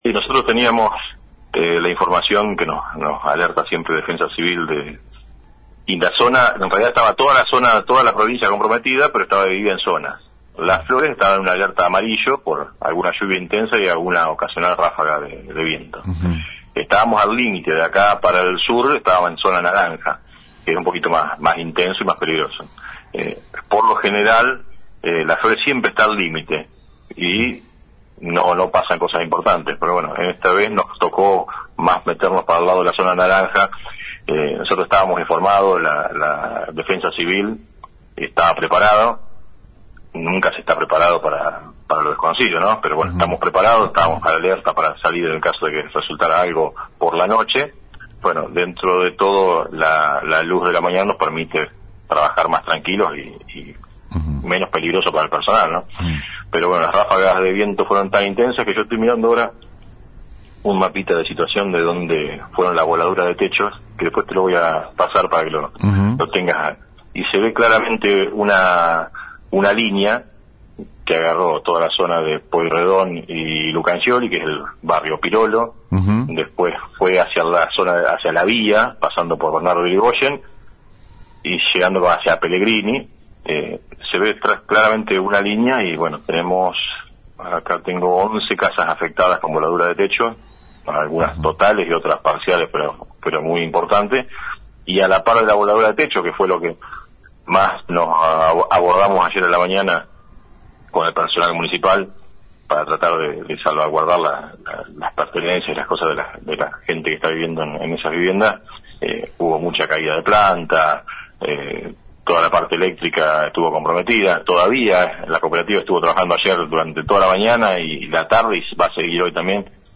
Después del fenómeno climatológico dado en la mañana del lunes, que dejó 11 domicilios sin techo en barrio “Pirolo”, y vientos que llegaron a los 100 k X hora, en la mañana de El Periodístico, hablamos vía telefónica con el Sec. De Obras Públicas del municipio de Las Flores Ing. Javier Arreyes.
Audio de la entrevista al Ing. Javier Arreyes